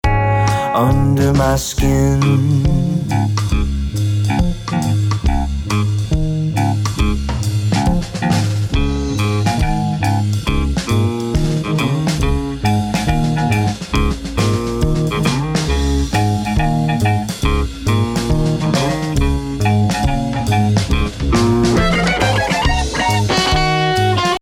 Guitar, bass guitar
Vocals
Buildup to solo